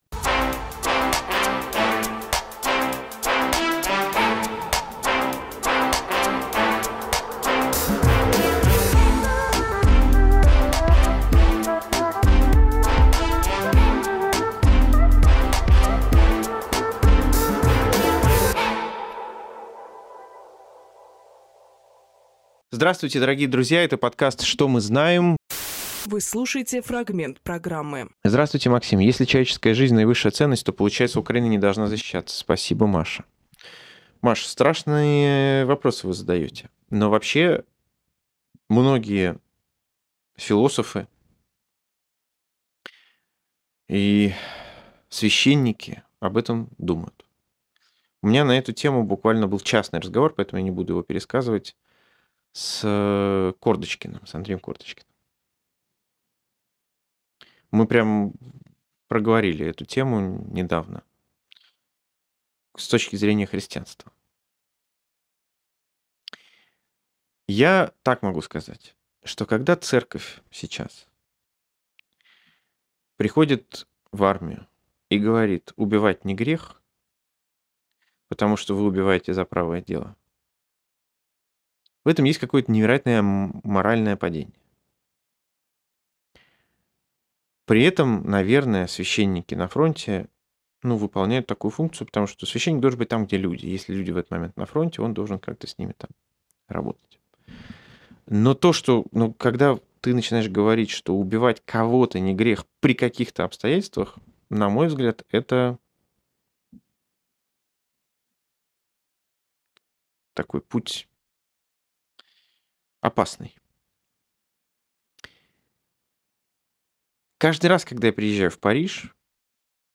Фрагмент эфира от 01.03.26